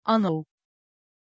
Aussprache von Anouk
pronunciation-anouk-tr.mp3